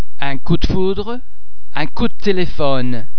the [d] under the influence of [c] [s] [f] and [t] is pronounced [t]